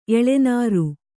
♪ eḷenāru